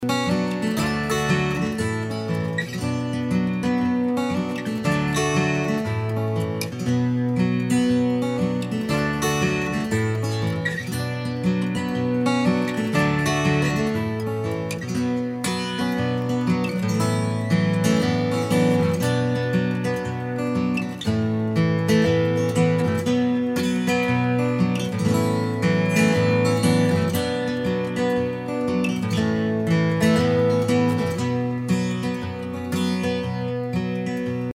at Sweet Silence Studios, Denmark,
fiddle & bouzouki